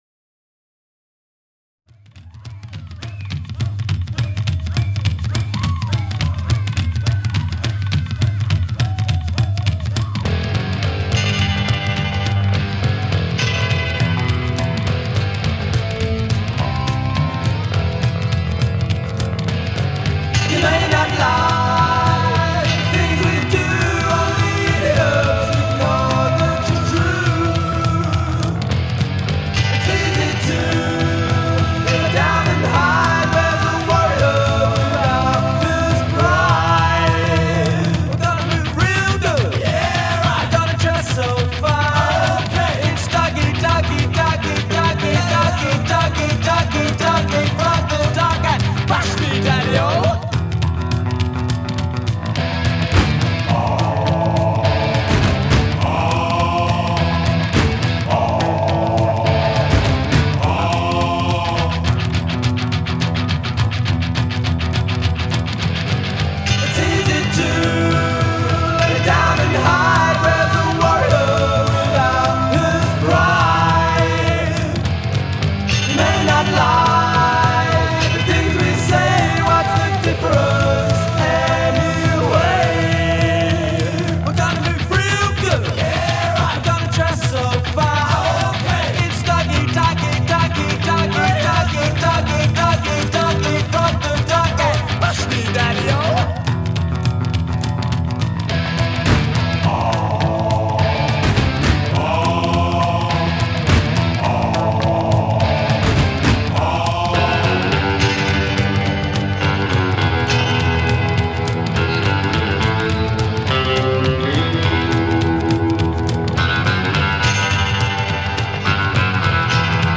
To a thumping double drummer Burundi beat